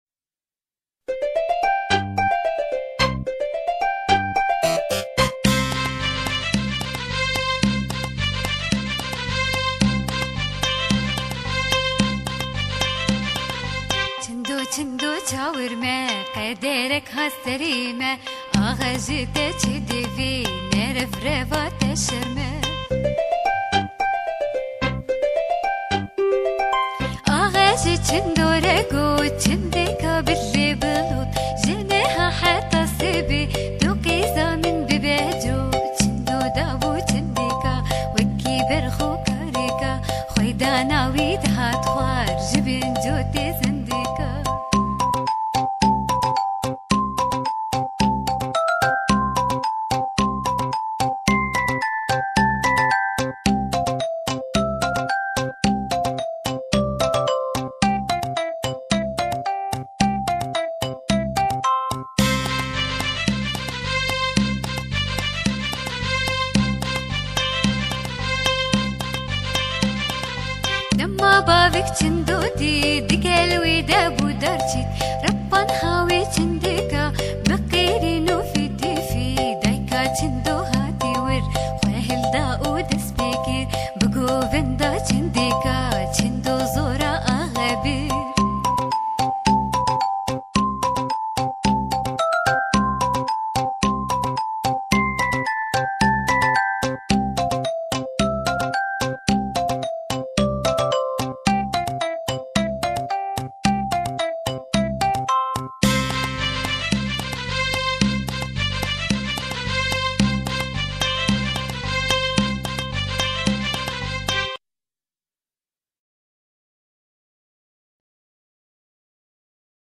çend stranên zarokan: